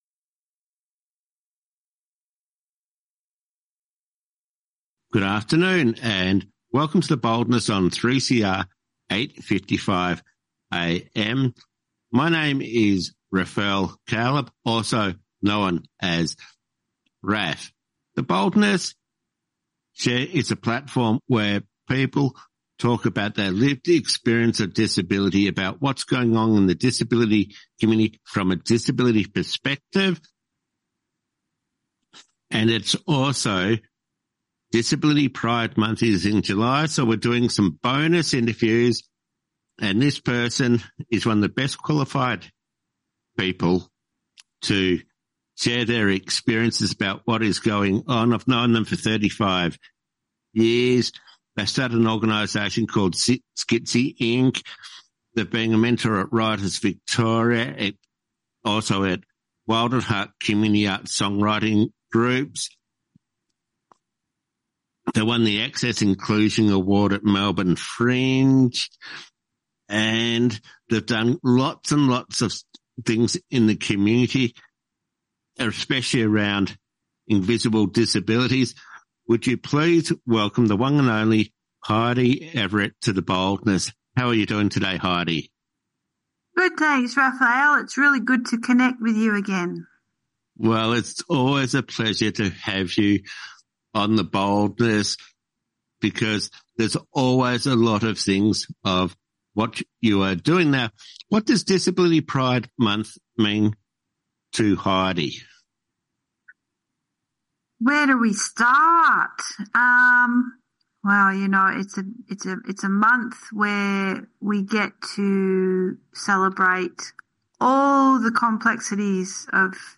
Disability issues with the Boldness radio team.